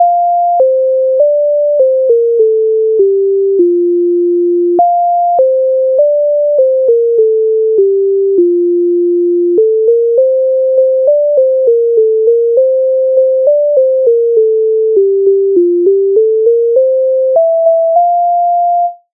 MIDI файл завантажено в тональності F-dur
Дівка в сінях стояла Українська народна пісня зі зб. михайовської